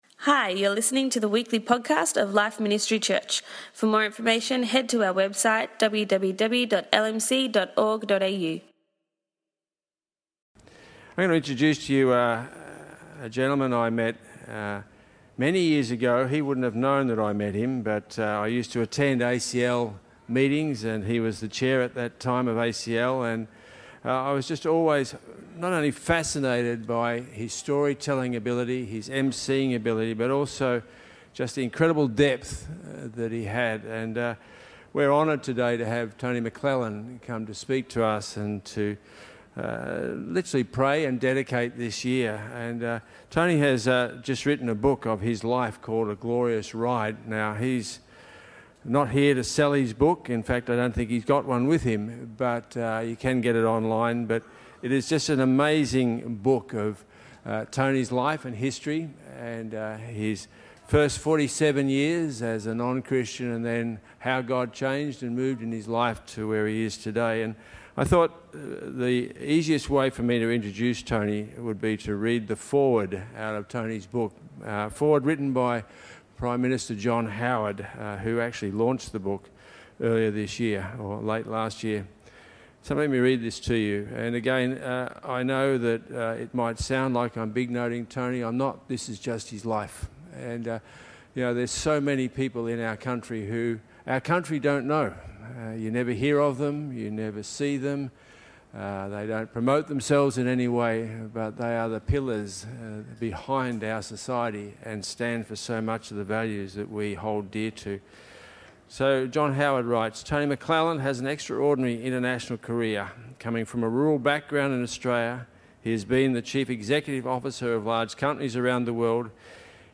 Effective Leadership - Dedication Sunday 2022